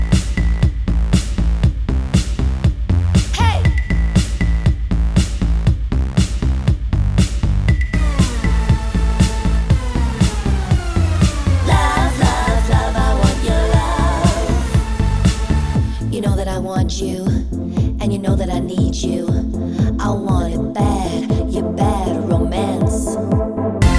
With Album Intro Pop